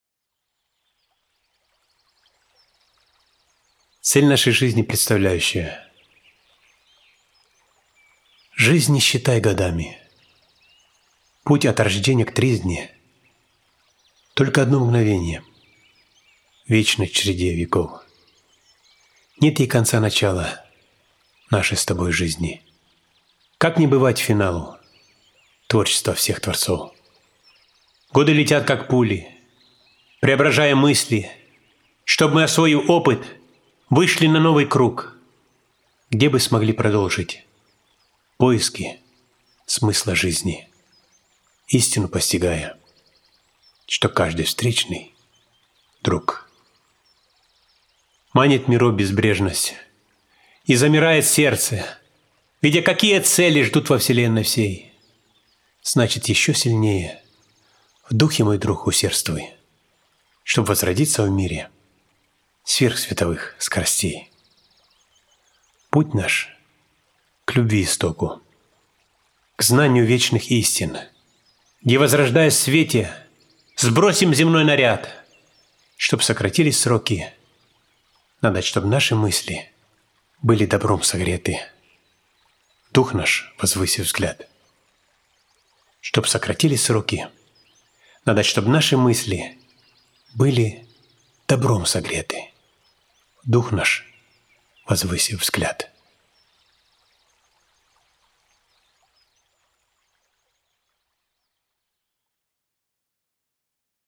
Стихи на песни у ручья